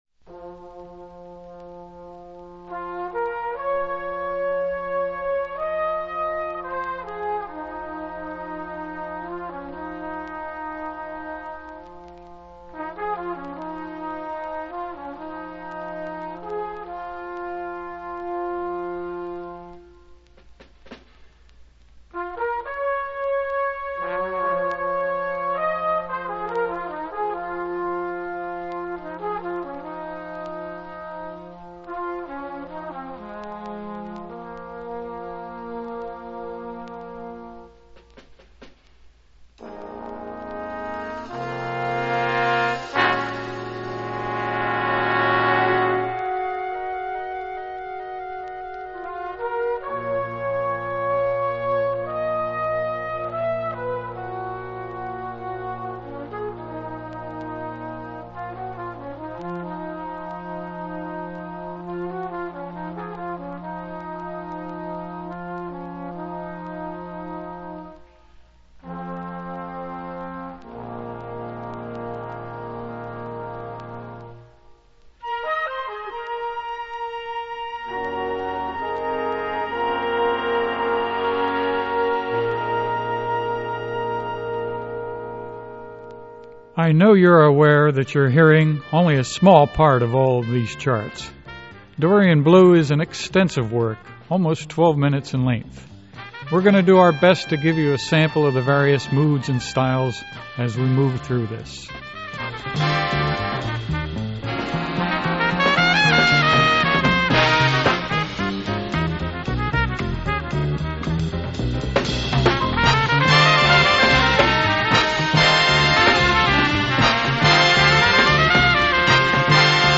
A monumental piece of big band jazz.
There are also piano solos.